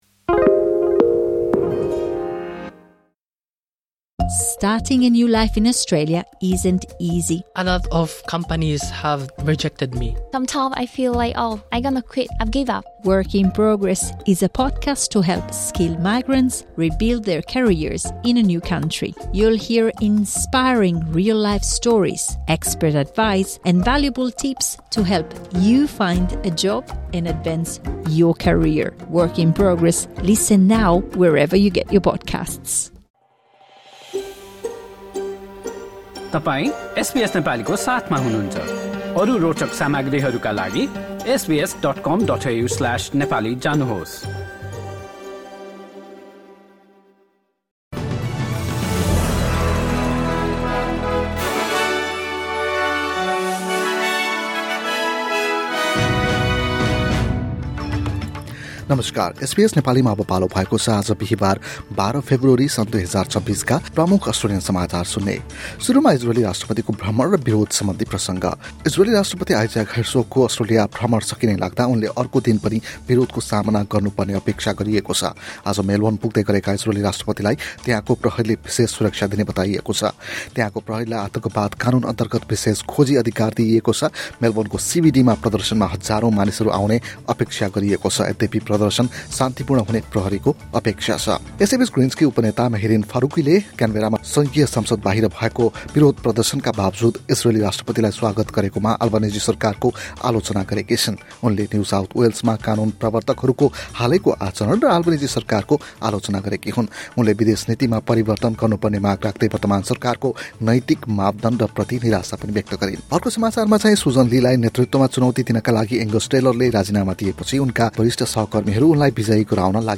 एसबीएस नेपाली प्रमुख अस्ट्रेलियन समाचार: बिहीवार, १२ फेब्रुअरी २०२६